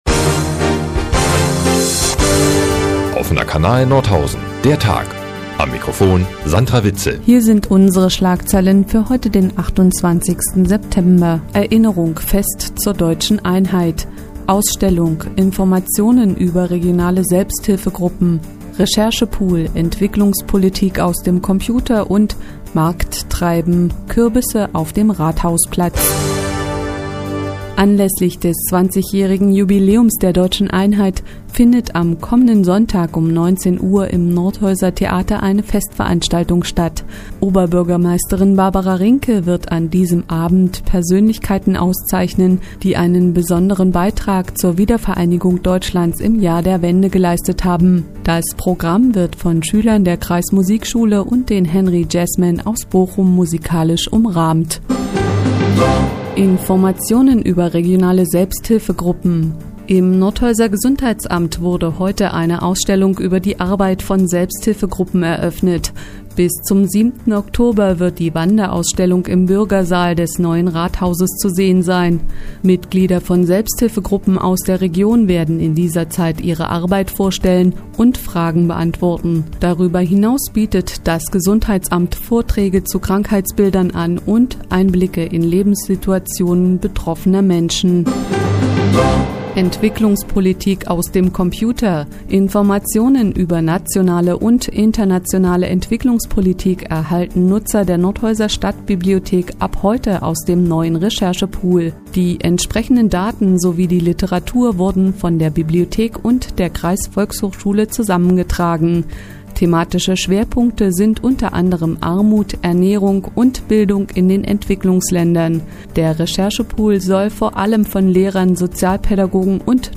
Die tägliche Nachrichtensendung des OKN ist auch in der nnz zu hören.